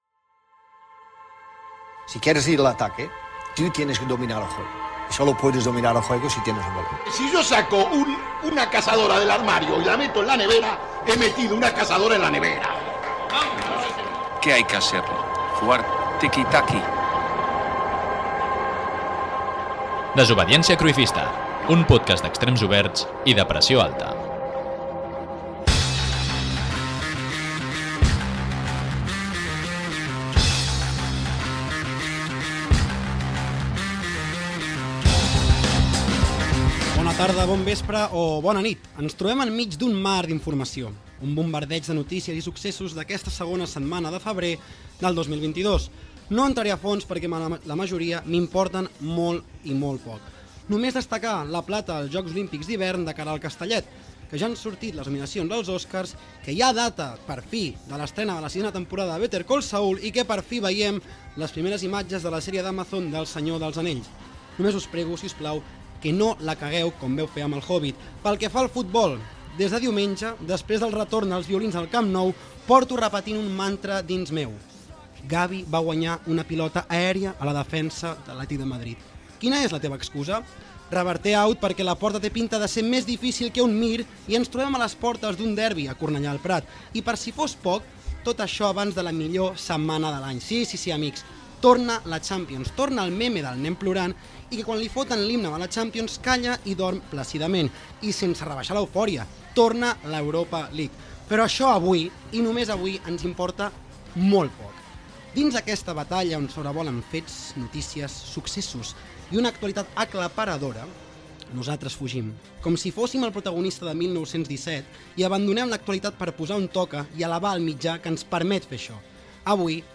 Careta del programa, repàs a l'actualitat, diàleg entre els integrants del programa, recull de fragments radiofònics cantant gols del primer equip masculí del Futbol Club Barcelona
Gènere radiofònic Esportiu